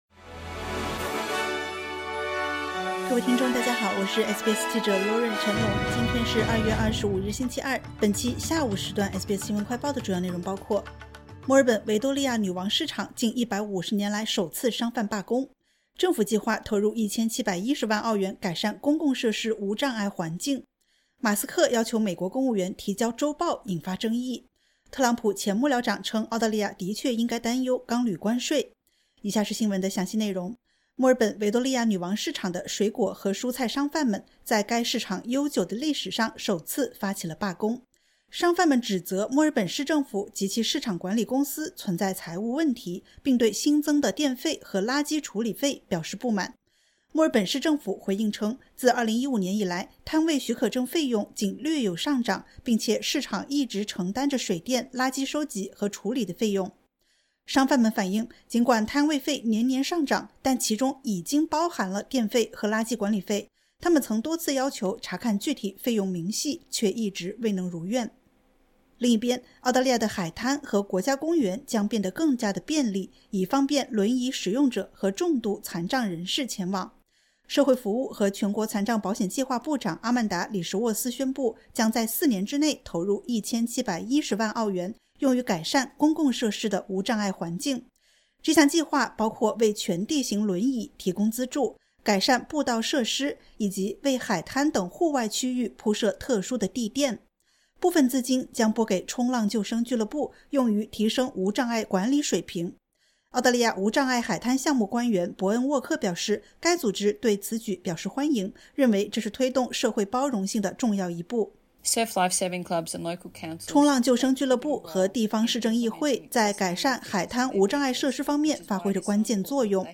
【SBS新闻快报】维多利亚女王市场商贩罢工 质疑费用上涨